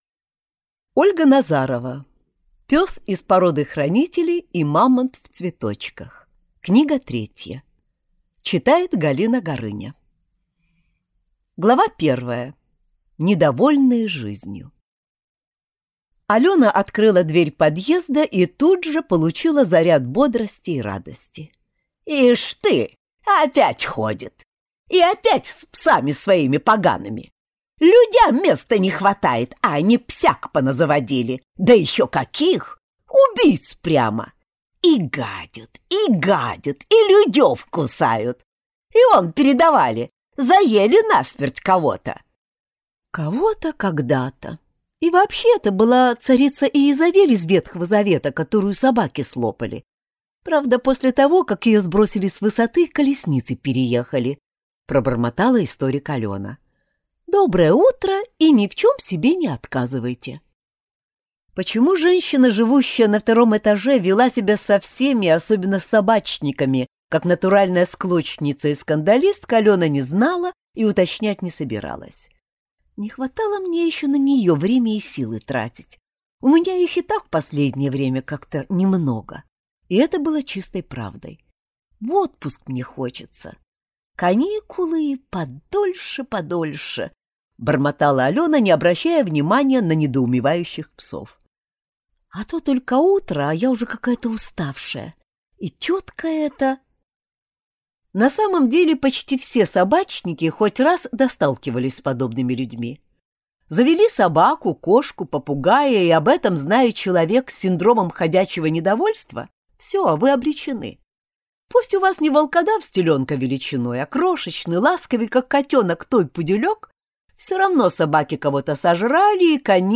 Аудиокнига Пёс из породы хранителей и мамонт в цветочках. Книга третья | Библиотека аудиокниг